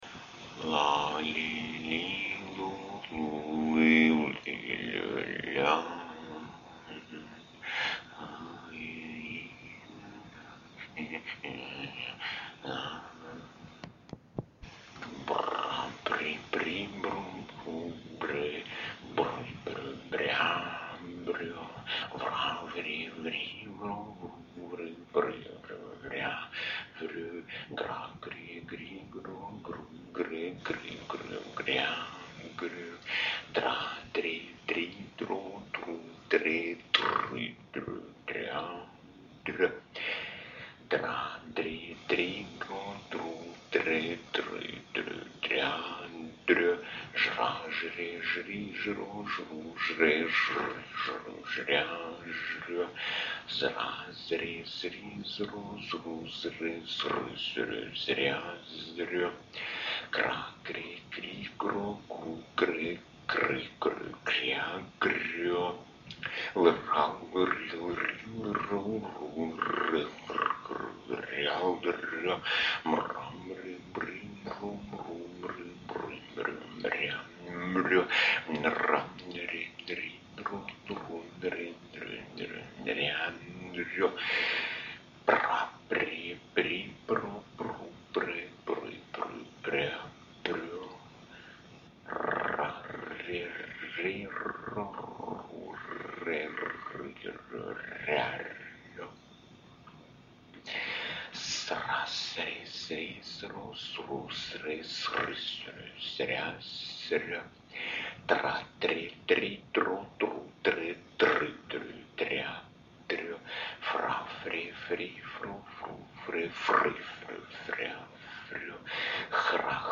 Unter anderem auch Sprechkasetten, die nur zum persönlichen Gebrauch als Kontroll- und Übematerial gedacht waren, aber durch ihre suggestive ruhige Kraft zum meditativen Mitvollzug einladen.
Blaue Übungskasette: Tonmaterial zum Hörspiel Matrjoschka, zweite Aufnahme:
lautmaterial-fur-das-horspiel-matrjoschka.mp3